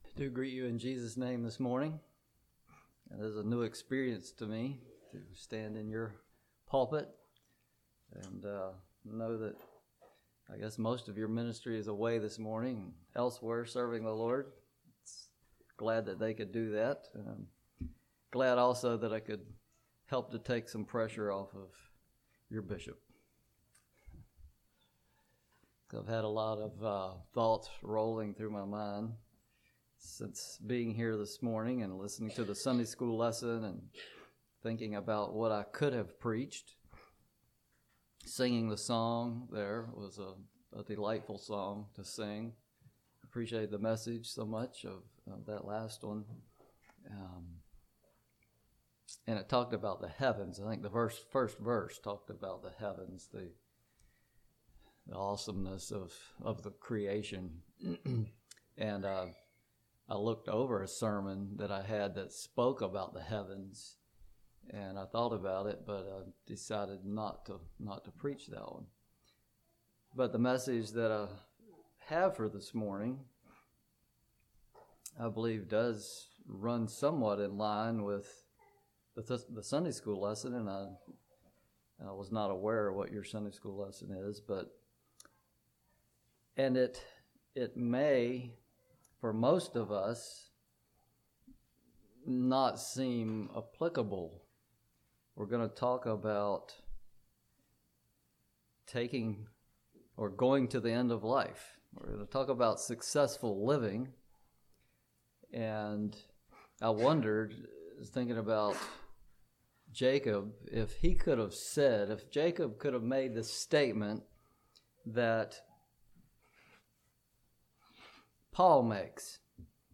This sermon gives instruction on how to finish well.